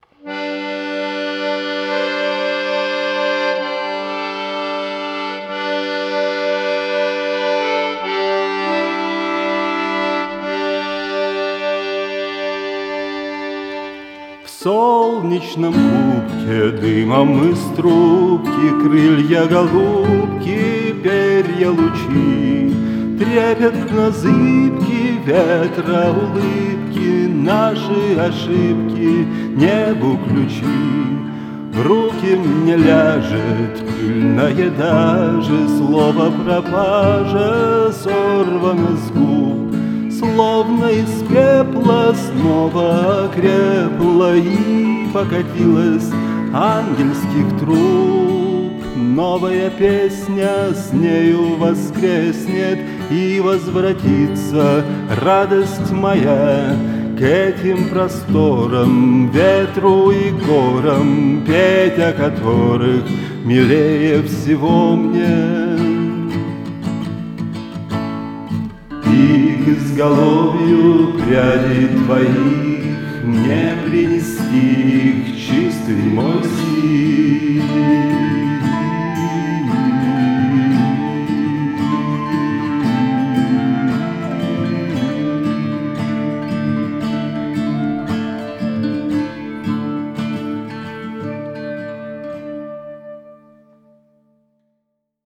Вот коротенькое демо песни ещё одного моего товарища, который ко мне захаживает на регулярной основе: Все источники записывались в этот микрофон с капсюлем второй модификации. Эквализация только на гитарах. На остальных источниках подрезаны только НЧ фильтром.